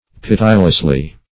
pitilessly - definition of pitilessly - synonyms, pronunciation, spelling from Free Dictionary
[1913 Webster] -- Pit"i*less*ly, adv.